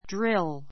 dríl